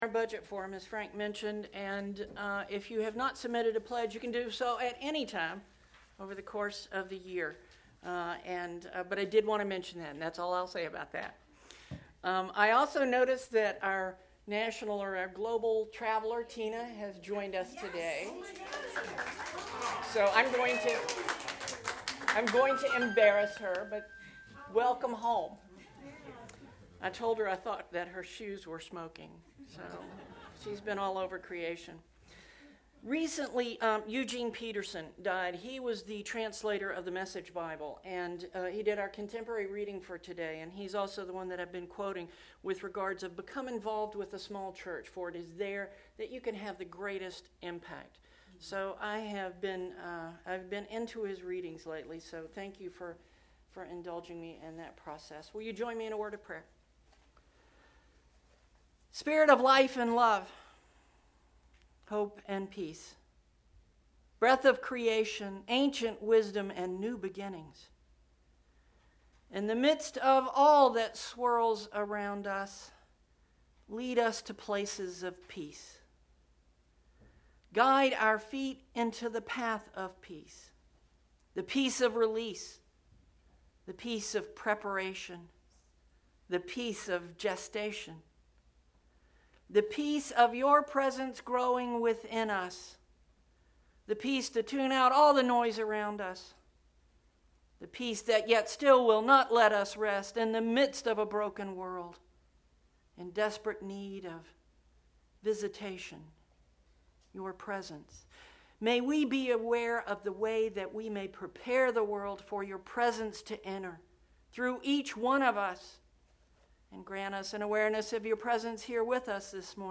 Sunday Dec 9th, 2018 – 2nd Sunday in Advent